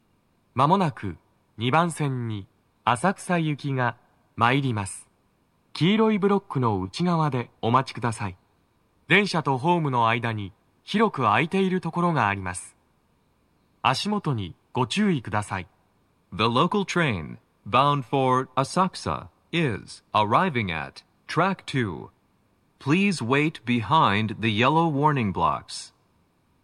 スピーカー種類 TOA天井型
🎵接近放送
鳴動は、やや遅めです。